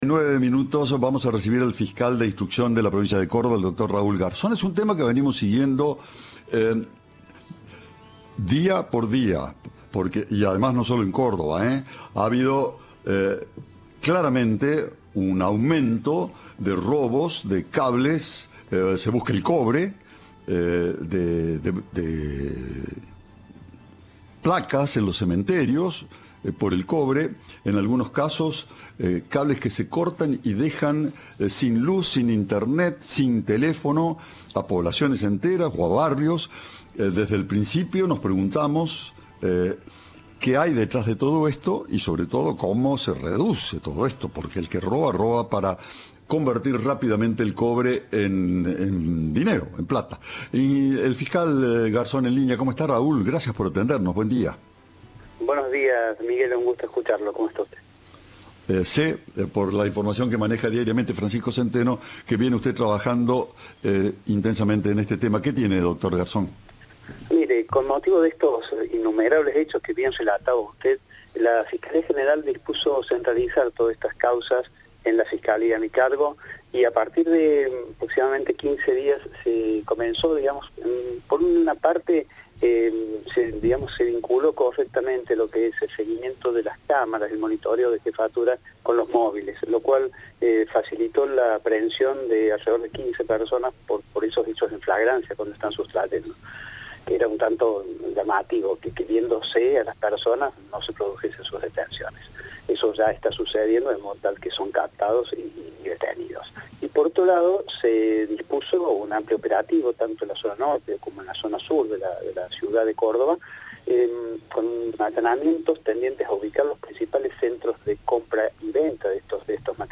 Unas 15 personas fueron detenidas por el robo de cables para extraer y vender el cobre, indicó a Cadena 3 Raúl Garzón, fiscal de instrucción de Córdoba.